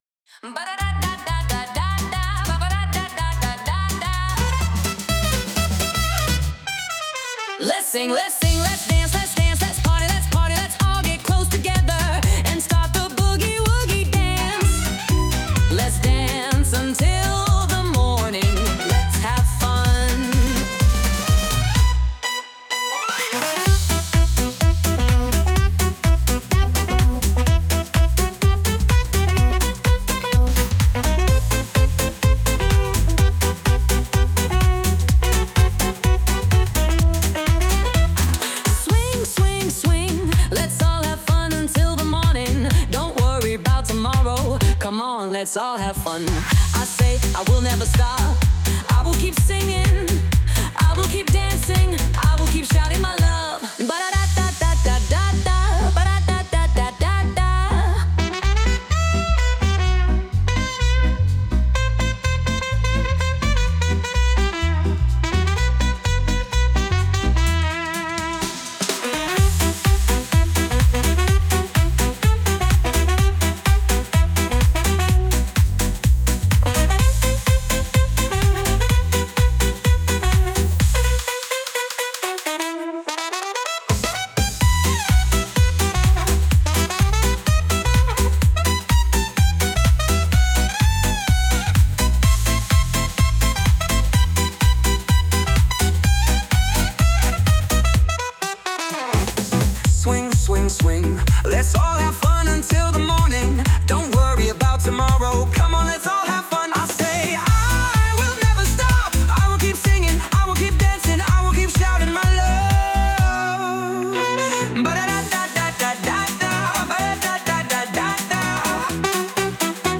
With Vocals / 歌あり